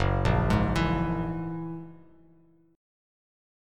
Gb9 Chord
Listen to Gb9 strummed